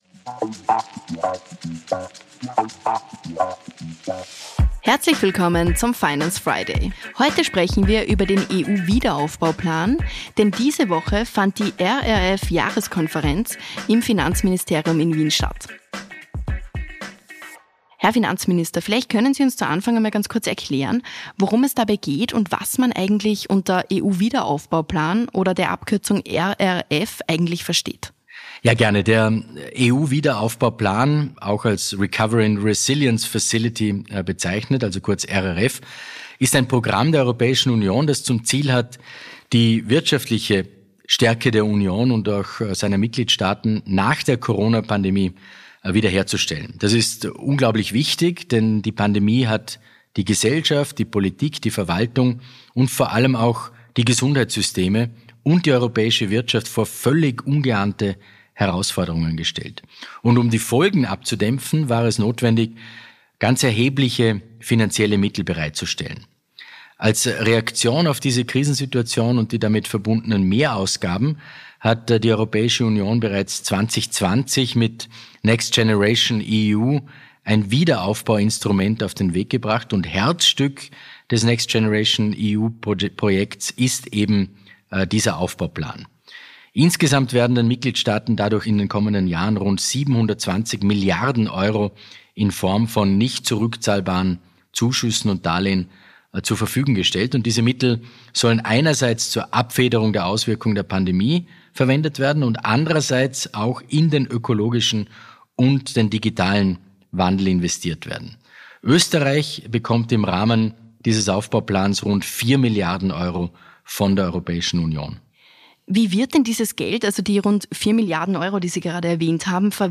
Finanzminister Magnus Brunner, was man unter der Abkürzung RRF